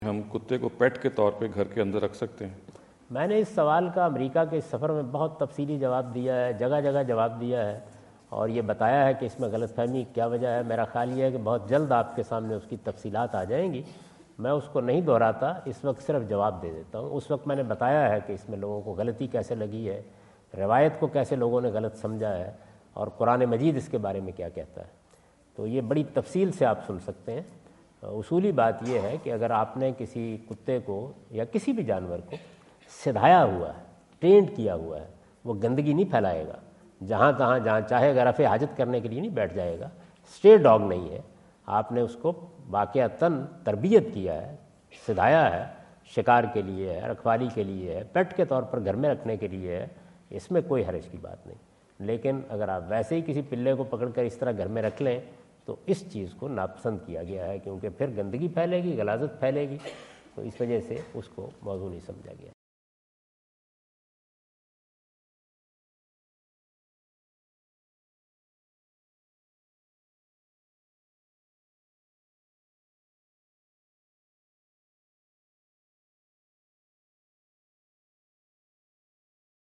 Category: English Subtitled / Questions_Answers /
In this video Javed Ahmad Ghamidi answer the question about "can we keep a pet dog inside the house?" asked at The University of Houston, Houston Texas on November 05,2017.